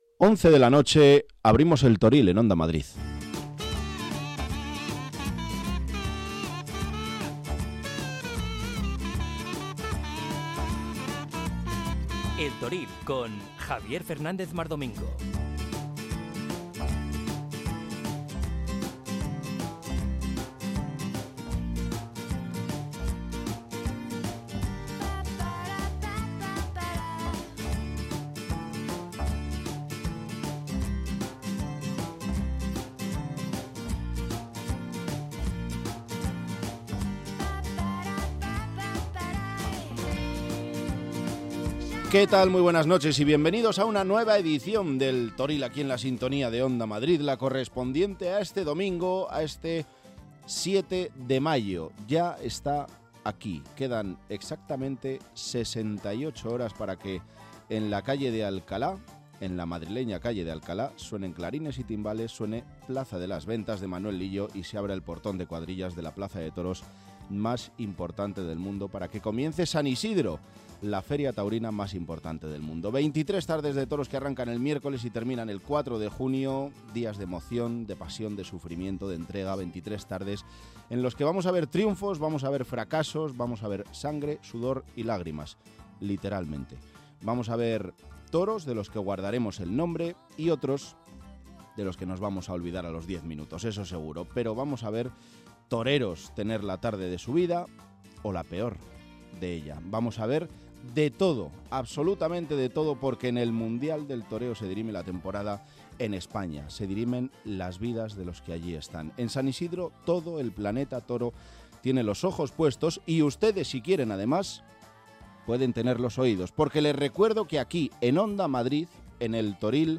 Habrá información pura y dura yentrevistas con los principales protagonistas de la semana.